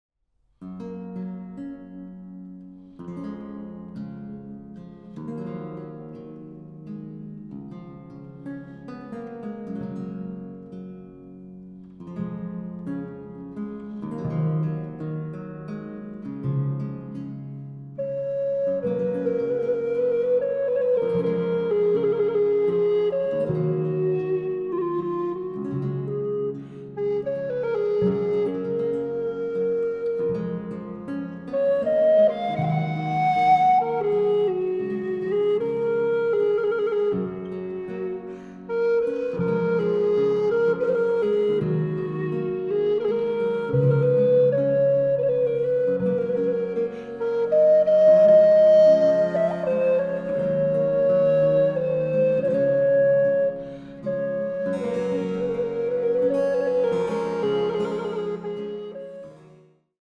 Flöte